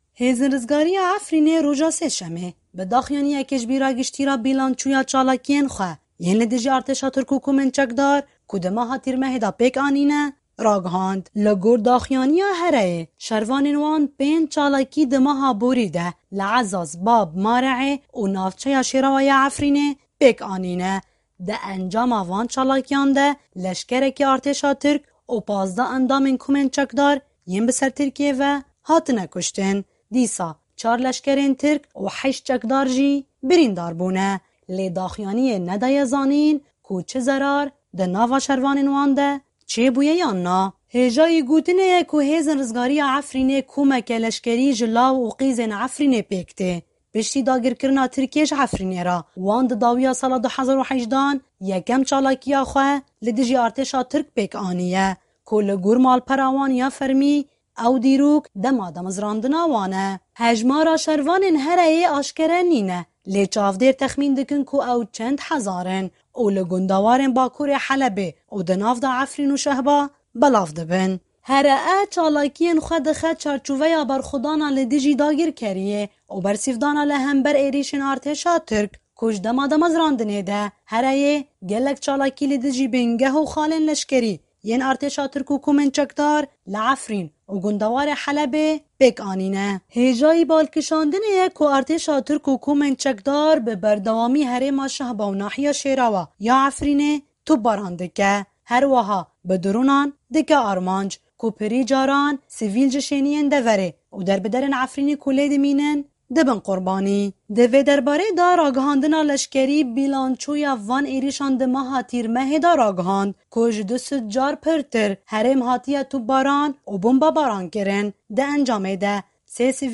Raporta Efrînê